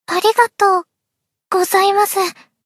贡献 ） 分类:蔚蓝档案语音 协议:Copyright 您不可以覆盖此文件。
BA_V_Tsukuyo_Battle_Buffed_1.ogg